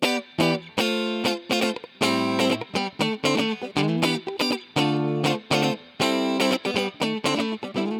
23 Guitar PT1.wav